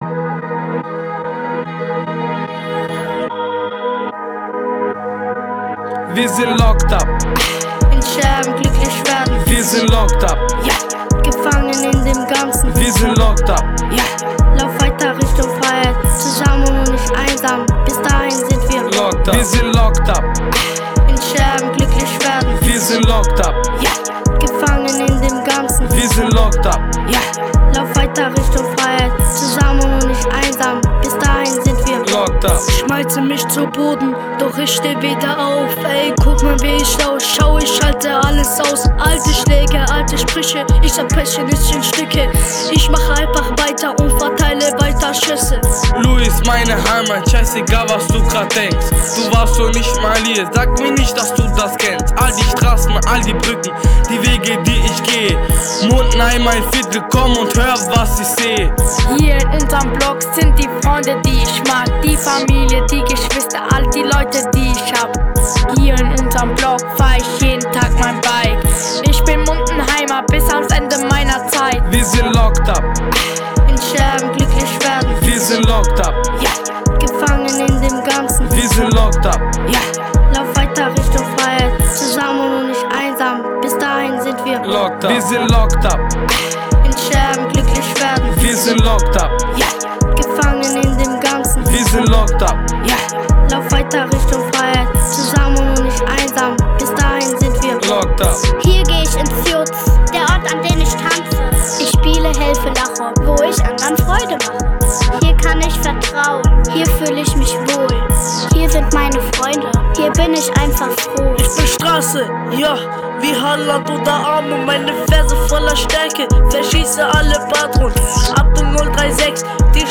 JUZ Mundenheim: Der neue HipHop Track „Locked up“ trifft mitten ins Herz!
Multikulturelles Hip Hop Projekt „Each One Teach One“ im JUZ Mundenheim